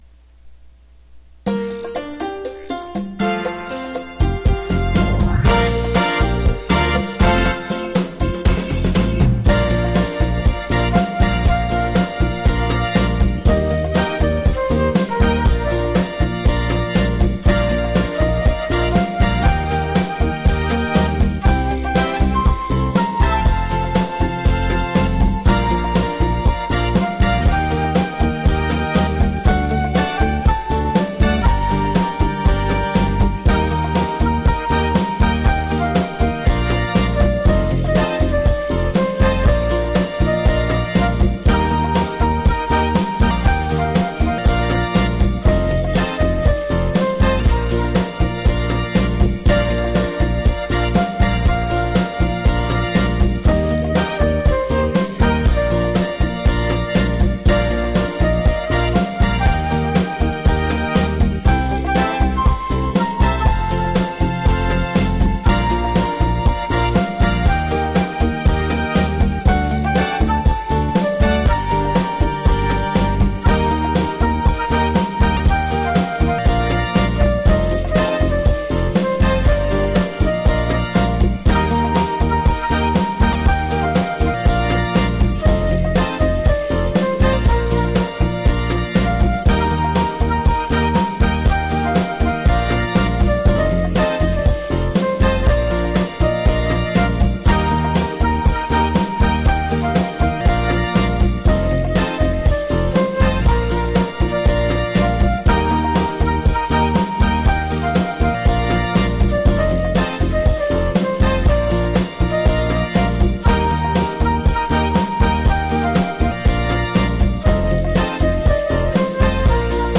[Accompaniment Track]       [Video]